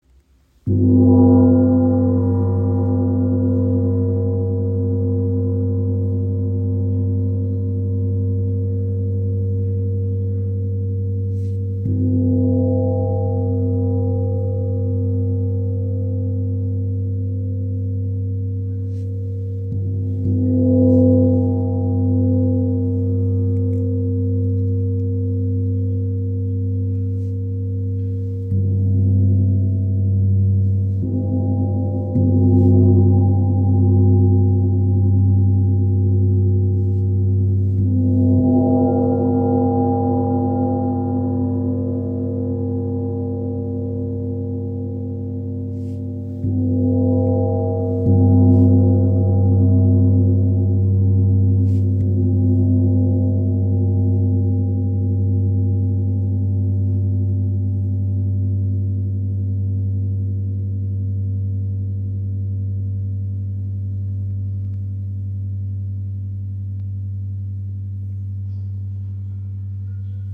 Handgefertigt von WOM Gongs in Italien – kraftvoller, klarer Klang.
Klangbeispiel
Sein voller Grundton und feine Obertöne erschaffen ein lebendiges Klangfeld, das Körper und Seele berührt.
Mit Reibungsschlägeln wie den B Love Flumies entstehen sanfte, traumhafte Wal- und Delfinsounds.